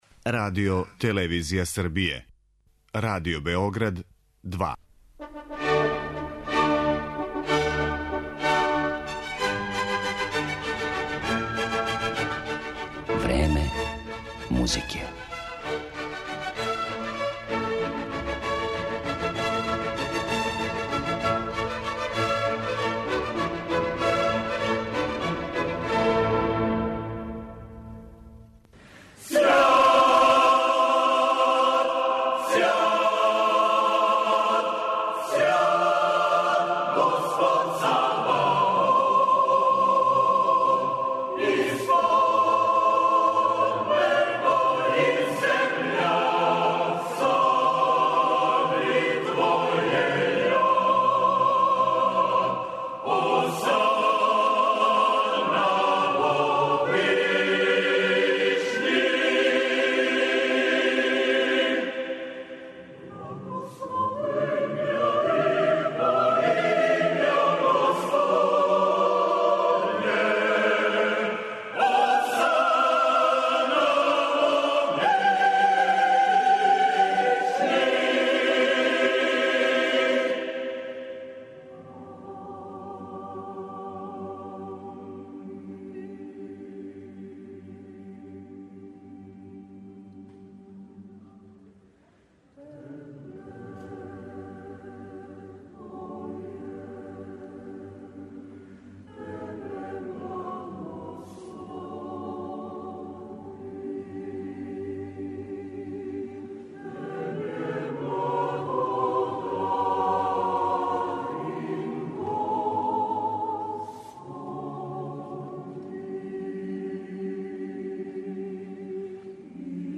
Тема за разговор са гостима у студију биће Интернационалне хорске свечаности, које се у Нишу одвијају од 3. до 6. јула.